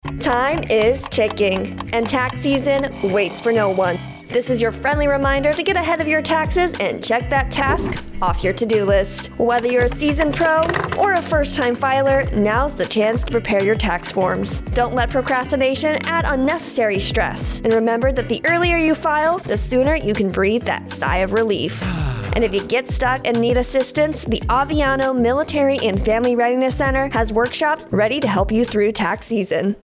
A radio spot urging members of Aviano Air Base to start filing taxes before tax day.